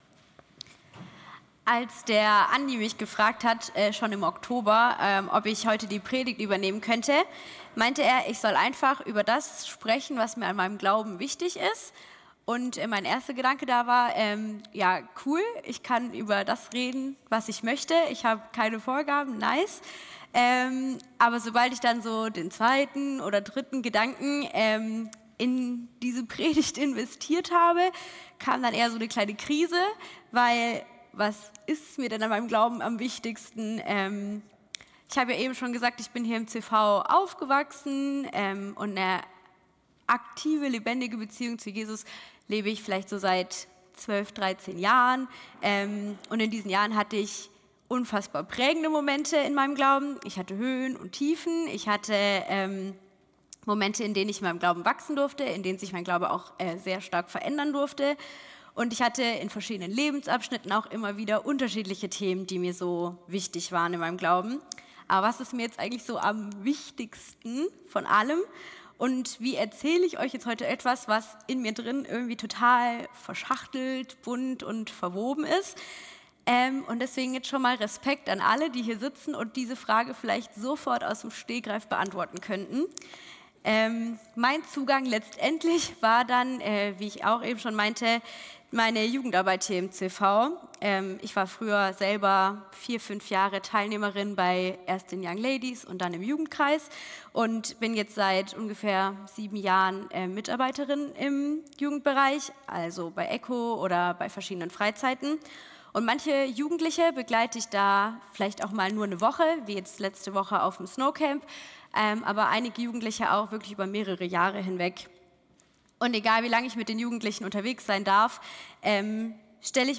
Hier erscheinen meist wöchentlich die Predigten aus dem Sonntags-Gottesdienst des CVJM Stuttgart